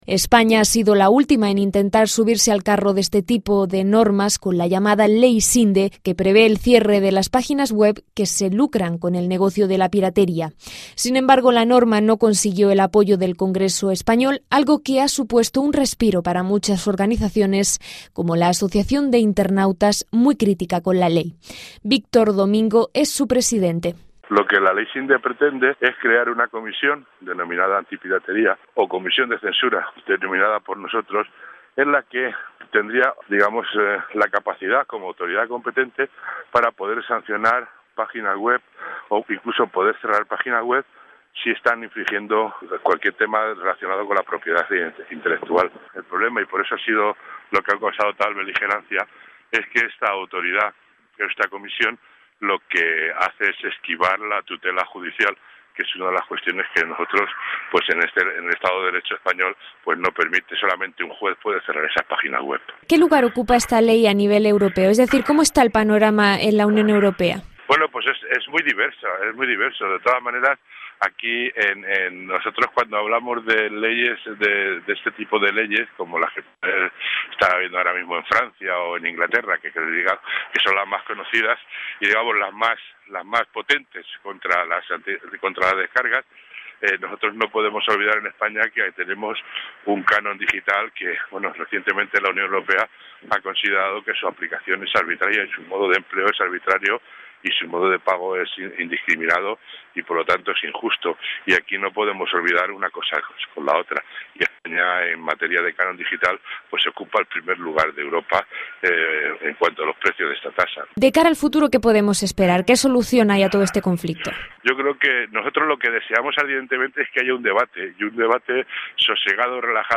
La Unión Europea está legislando contra los usuarios que comparten archivos protegidos por el derecho de autor. Escuche el informe de Radio Francia Internacional.